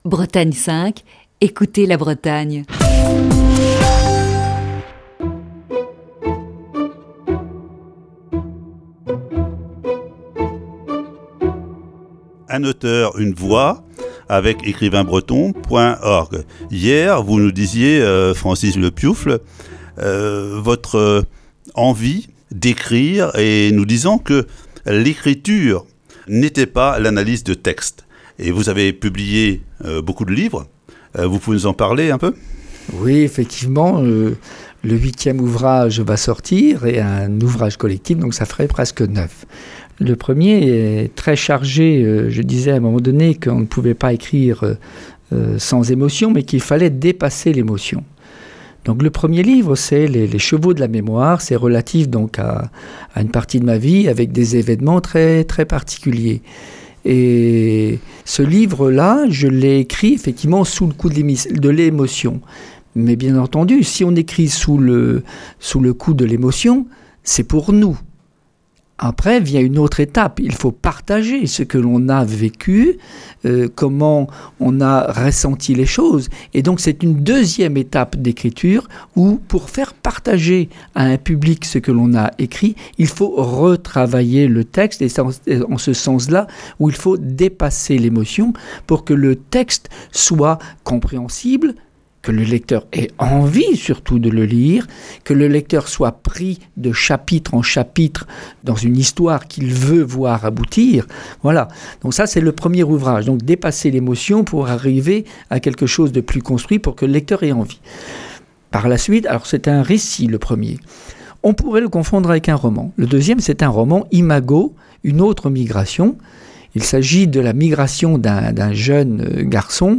Ce matin, voici la quatrième partie de cette série d'entretiens.